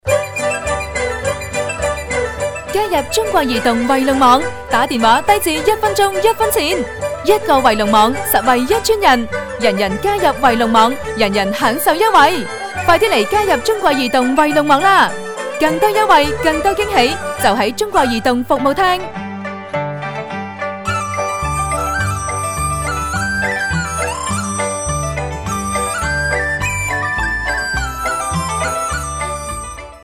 Kantonca (Honkong) Seslendirme
Kadın Ses